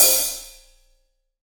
Index of /90_sSampleCDs/AKAI S6000 CD-ROM - Volume 3/Drum_Kit/AMBIENCE_KIT3
AMB OPHH1 -S.WAV